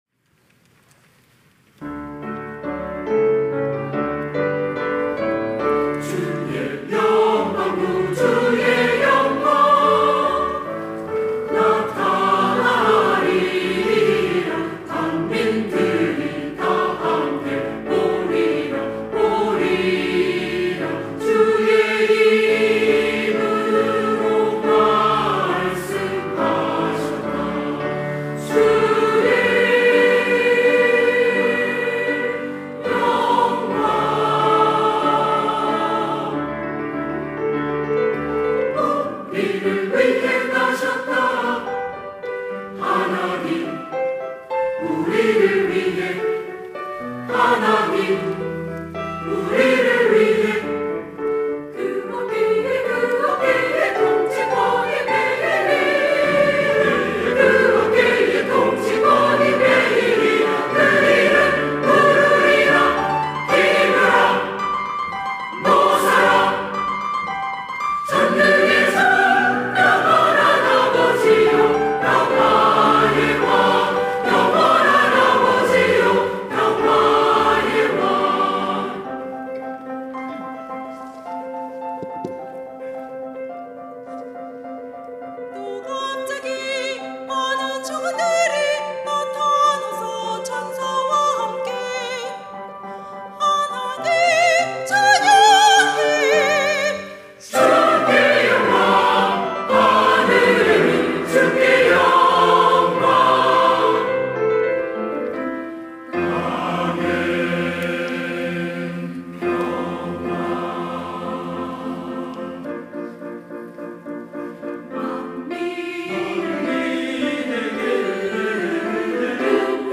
시온(주일1부) - 메시야 메들리
찬양대